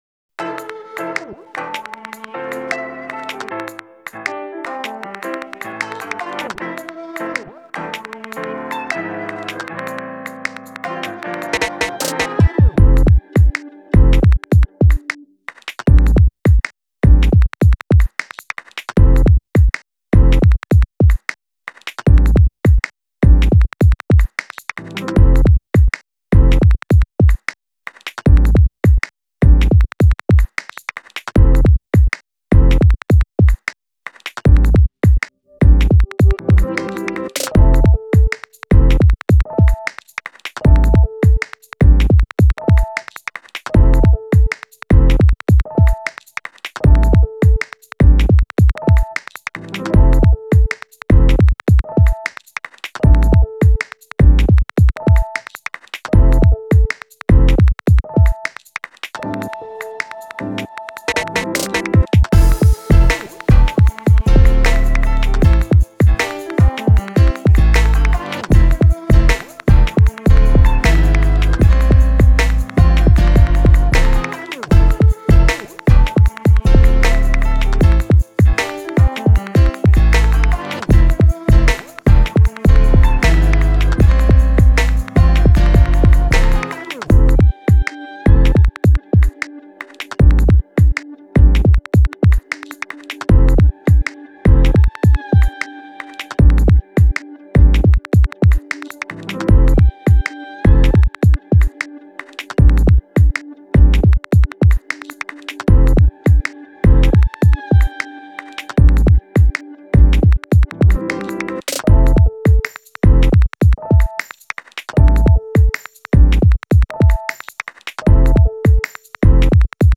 Type Beat